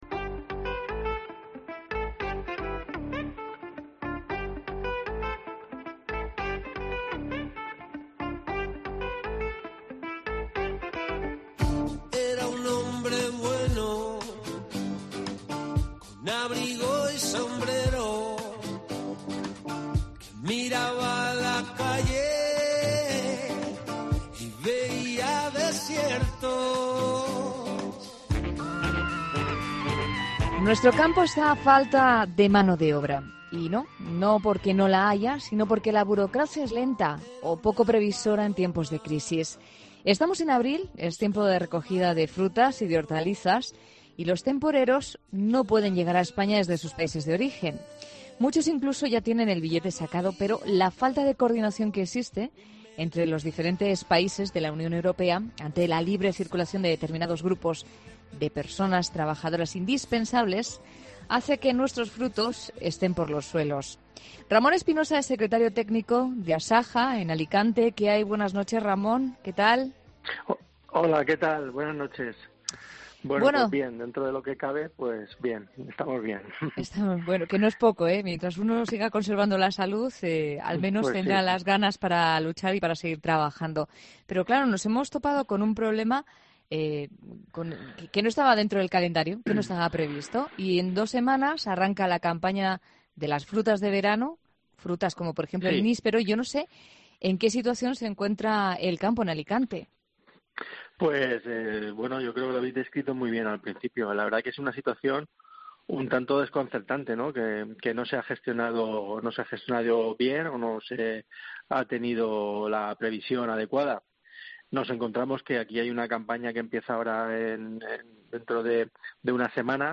Agricultores acercan en 'La Noche' la situación que se está viviendo en estos momentos